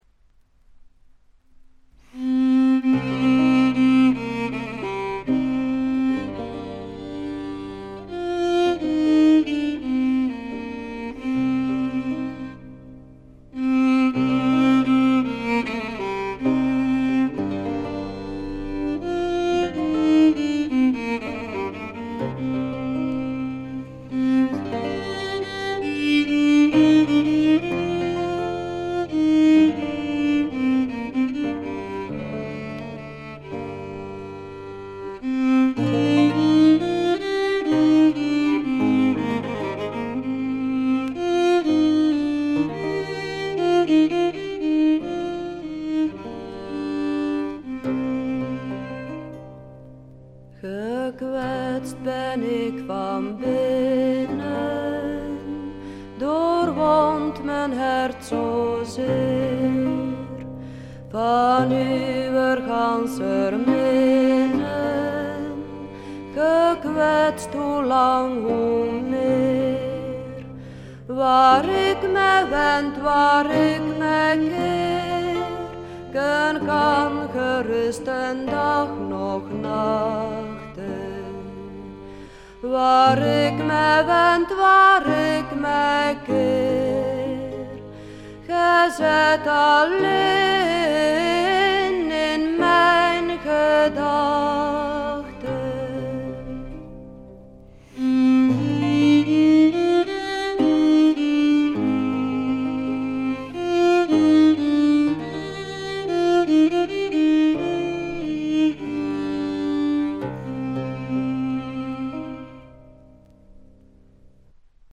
ほとんどノイズ感無し。
試聴曲は現品からの取り込み音源です。